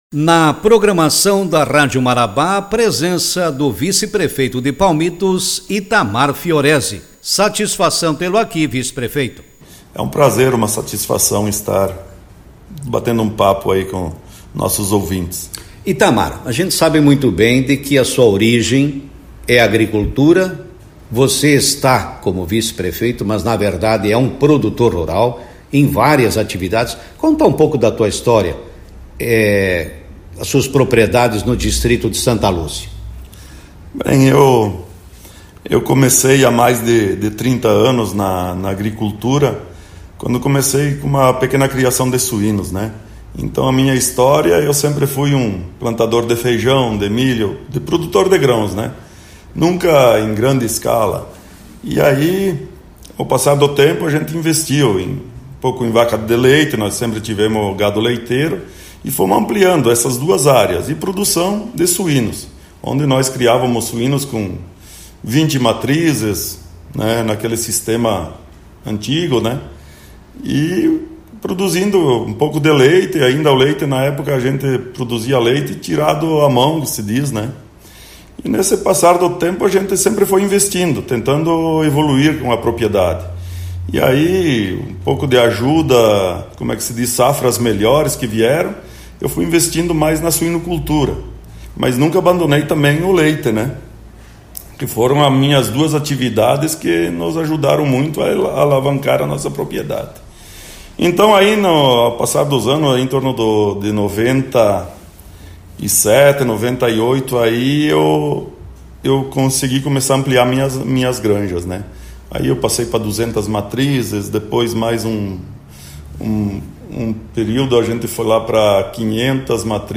ITAMAR FIOREZE, Vice-Prefeito de Palmitos, concedeu entrevista